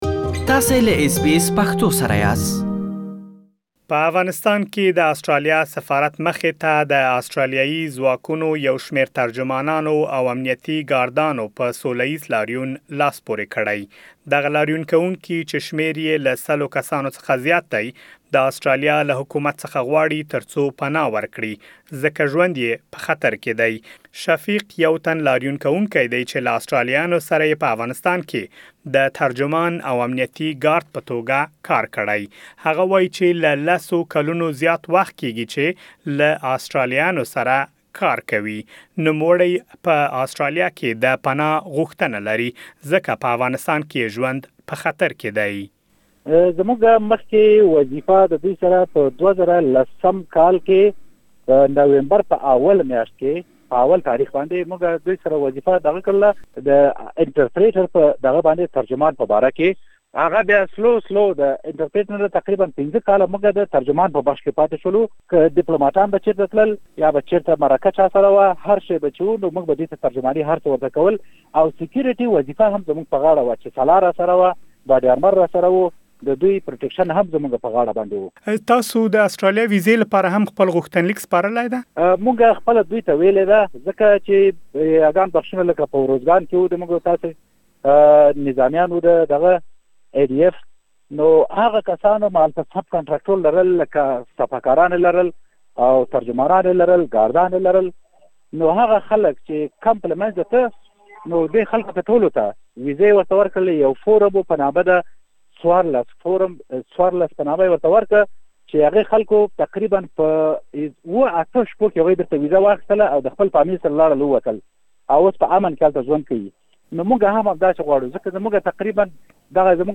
تاسو کولای شئ، د لاریون کوونکو خبرې په رپوټ کې واورئ.